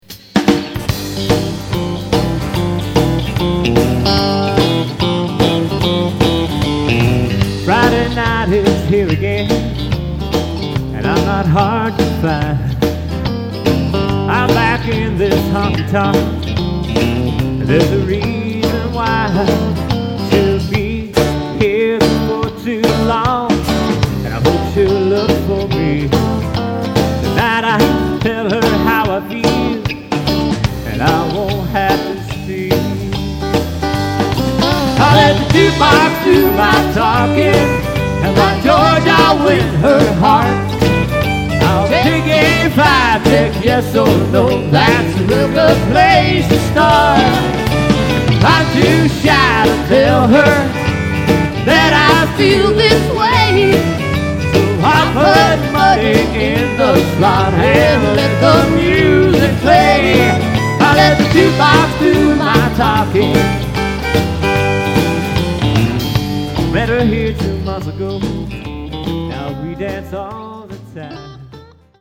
Style: Honky Tonk, Country, Country/Rock